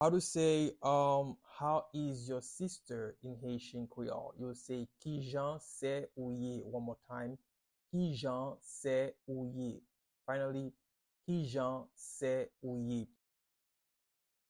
Pronunciation and Transcript:
How-is-your-sister-in-Haitian-Creole-–-Kijan-se-ou-ye-pronunciation-by-a-Haitian-Creole-teacher.mp3